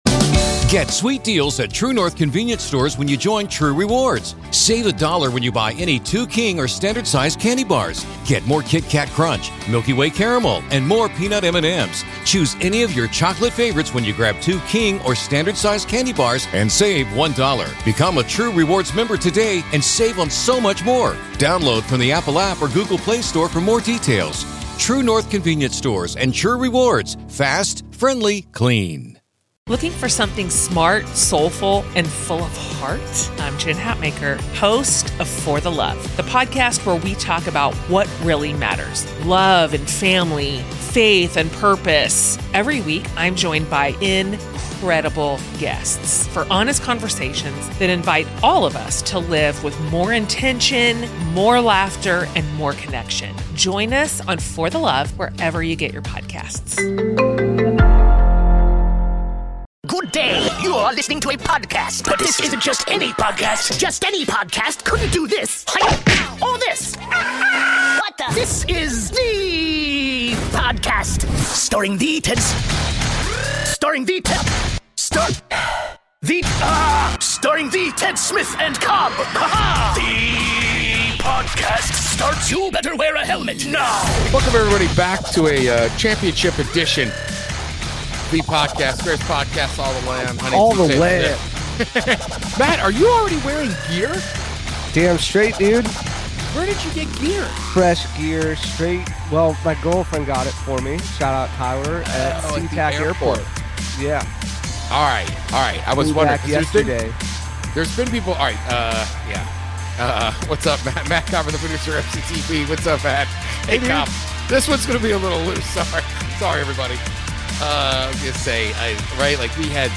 Thee podcast is a journey to awesomeness led by two men and their producer.